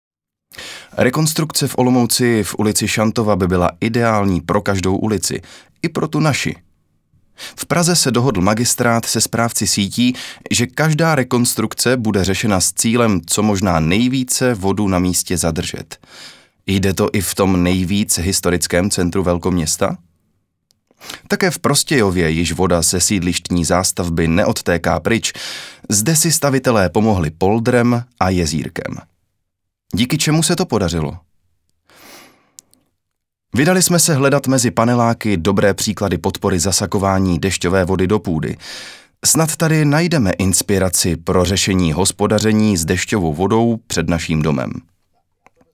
ukázka voice over:
voice-over.mp3